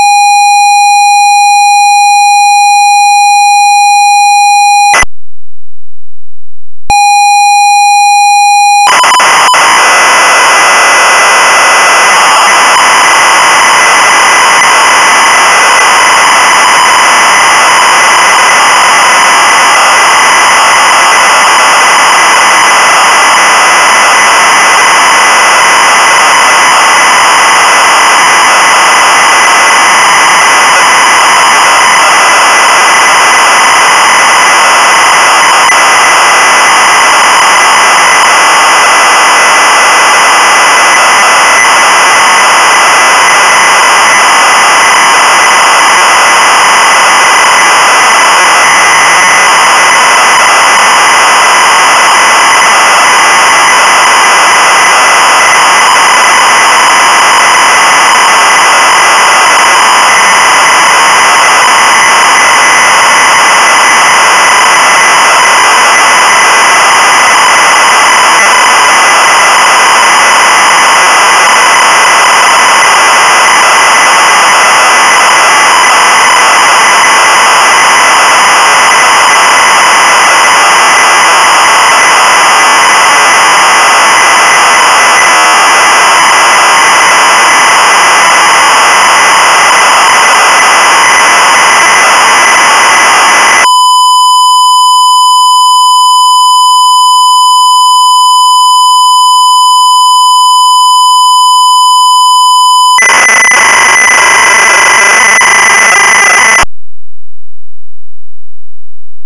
Puteți utiliza unul din fișierele de tip ROM atașate (cu extensia WAV) pentru a încărca sistemul de operare pe un computer CIP-01.
(4.59 MB) - ROM original ZX Spectrum inclus pe caseta demonstrativă